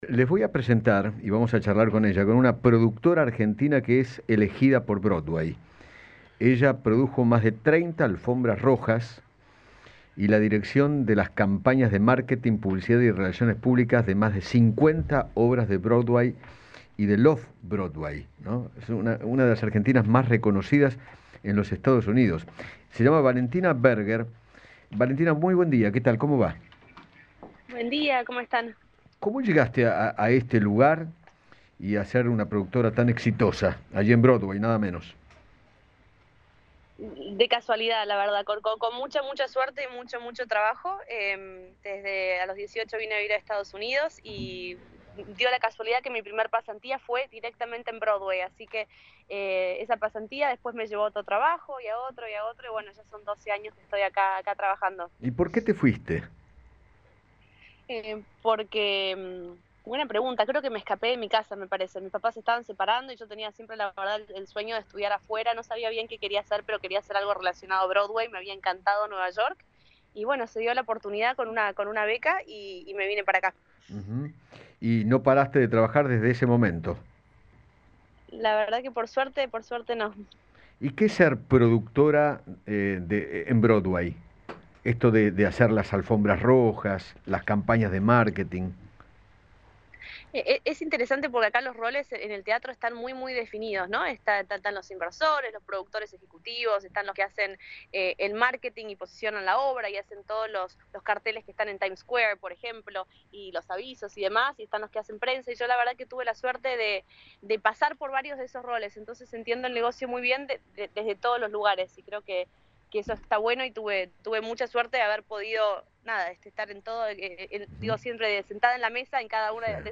Eduardo Feinmann dialogó con